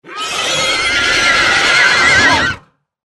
Скачивайте рыки, рев, тяжелое дыхание и крики фантастических существ в формате MP3.
Писк демона